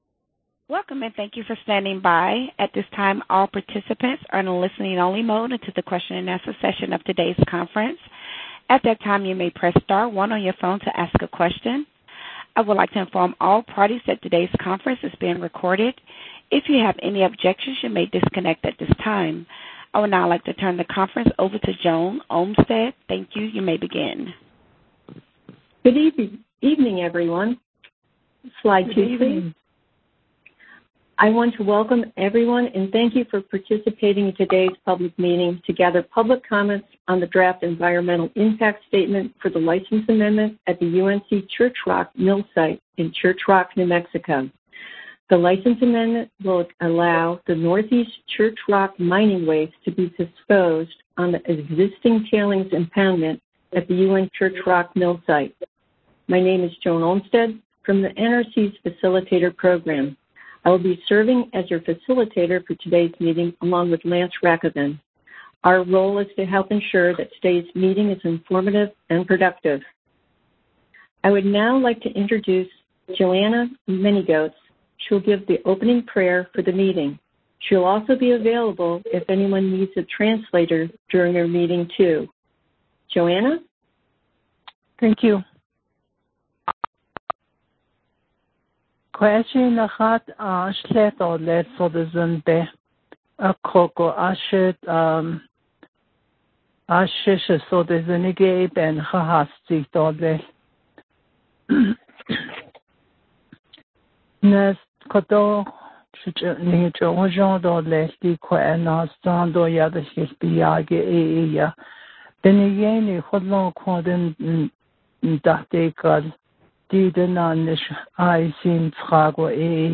April 29, 2021 webinar/teleconference
Public Meeting Audio Recording